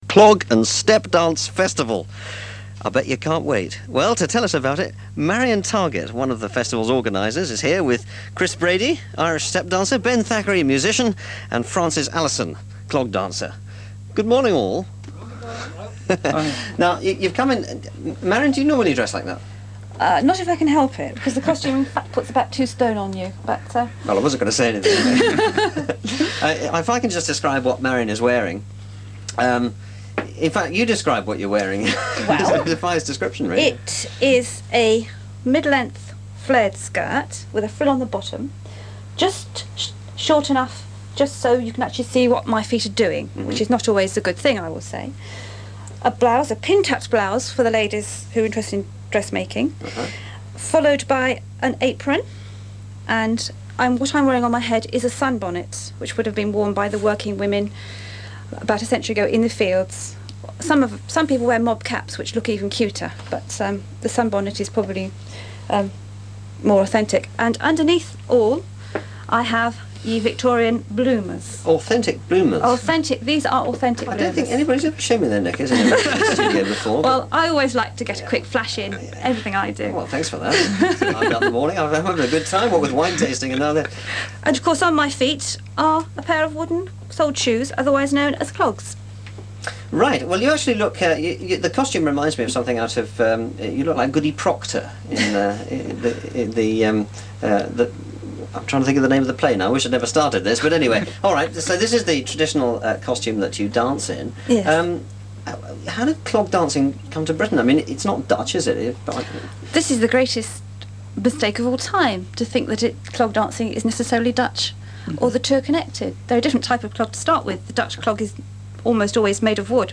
Reading Cloggies' Festival - 1994
reading_fest_1994.wma